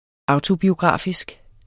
Udtale [ ˈɑwto- ]